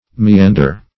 Meander \Me*an"der\, v. t.